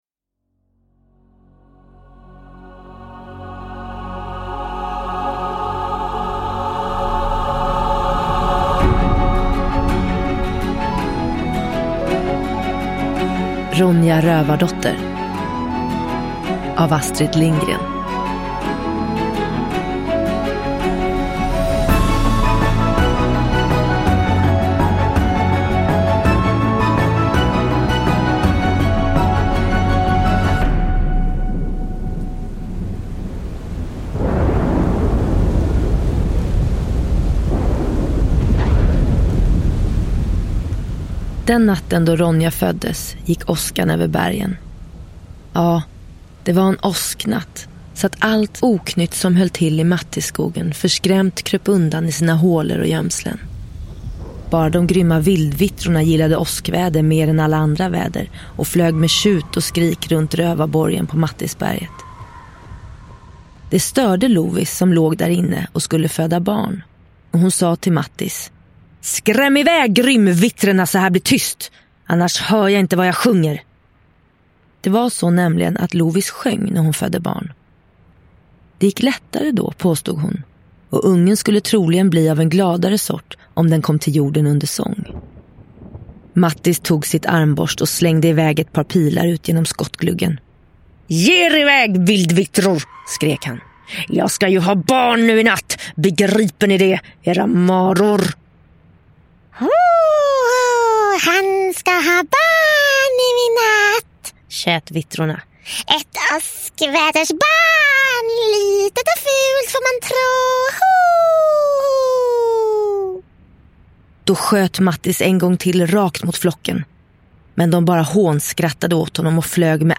Ronja Rövardotter – Ljudbok
Nyinläsning av Aliette Opheim och med stämningsfullt ljudlagda scener.
Uppläsare: Aliette Opheim